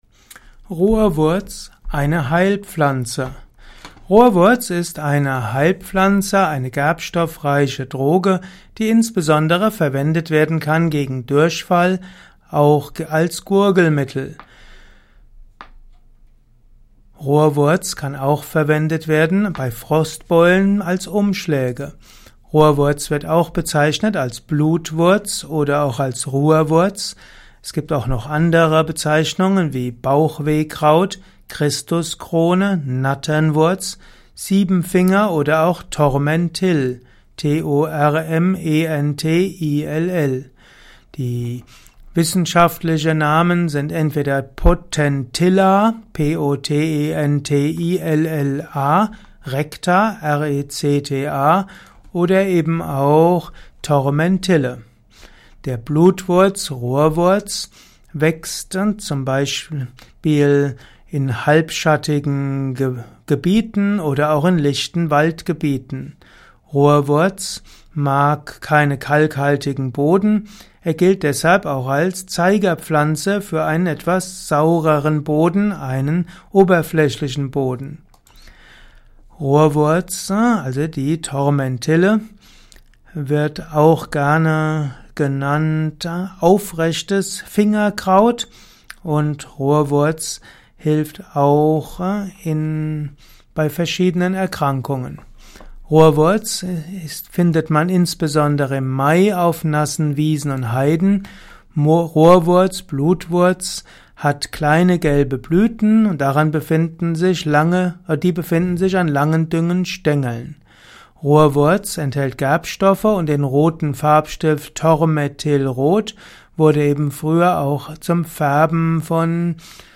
Audiovortrag mit einigen Erörterungen und Darlegungen zu Rohrwurz. Höre einiges rund um Rohrwurz in dieser Kurzabhandlung.